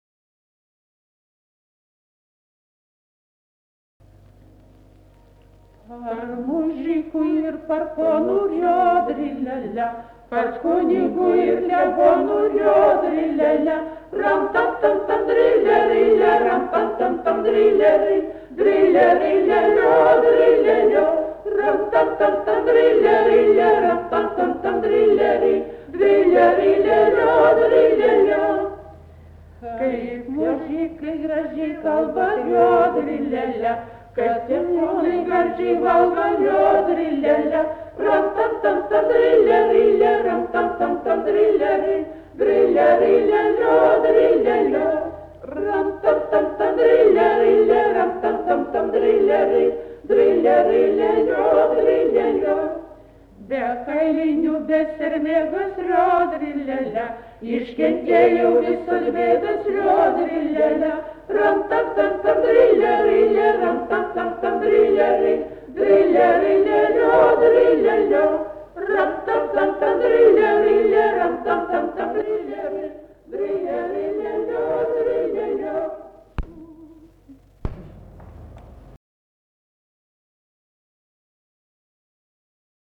Erdvinė aprėptis Anykščiai
Atlikimo pubūdis vokalinis
Piemenų šūksnis prieš lietų